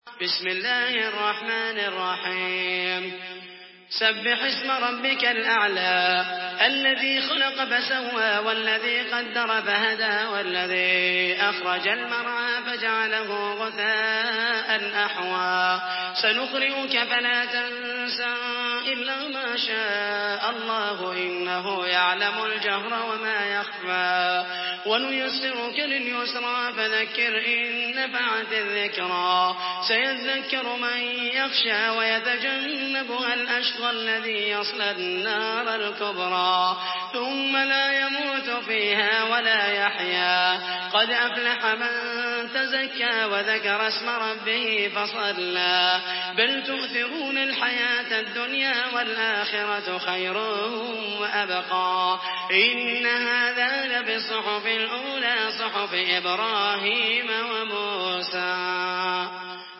تحميل سورة الأعلى بصوت محمد المحيسني
مرتل حفص عن عاصم